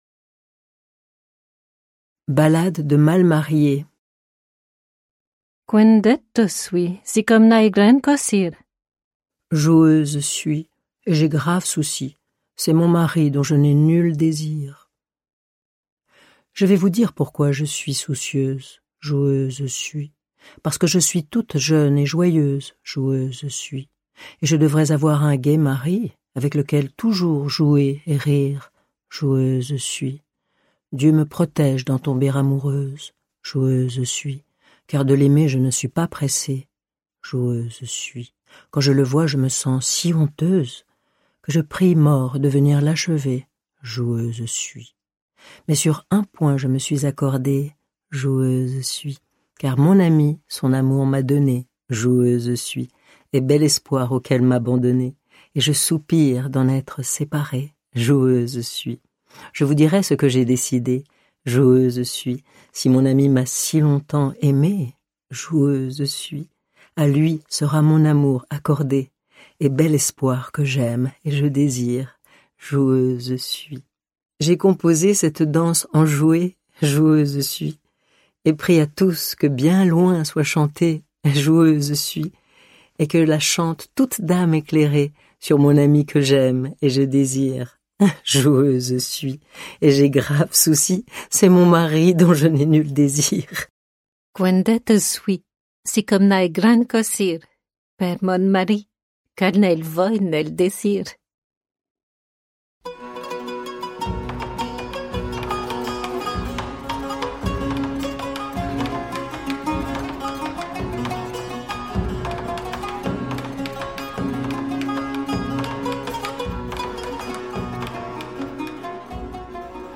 Cette lecture à deux voix, en occitan et en français, vous emportera au pays de la poésie de Béatrice de Die, Na Castelosa, Tibors de Sérianon, Azalaïs de Portiragnes, Clara d’Anduze, Marie de Vantadour et tant d’autres dames illustres ou anonymes du temps jadis.